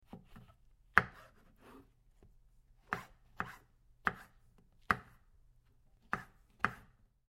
Звуки грибов
Режем грибы перед готовкой на мелкие кусочки